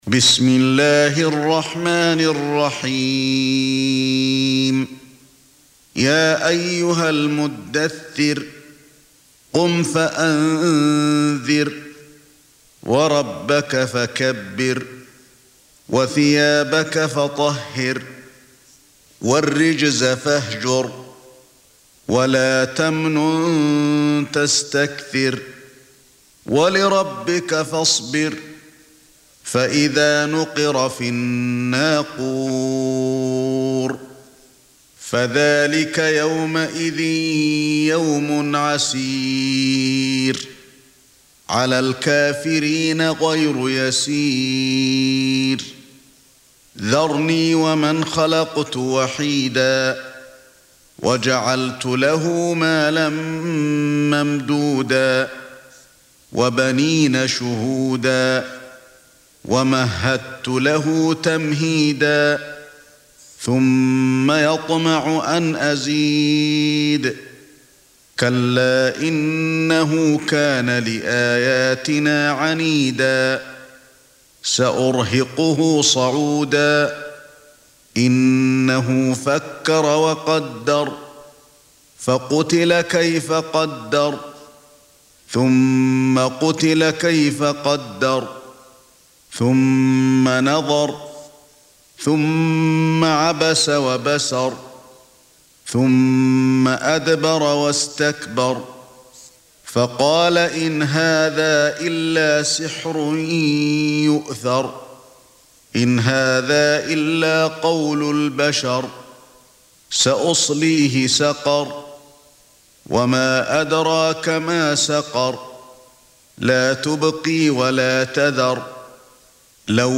74. Surah Al-Muddaththir سورة المدّثر Audio Quran Tarteel Recitation
Surah Sequence تتابع السورة Download Surah حمّل السورة Reciting Murattalah Audio for 74. Surah Al-Muddaththir سورة المدّثر N.B *Surah Includes Al-Basmalah Reciters Sequents تتابع التلاوات Reciters Repeats تكرار التلاوات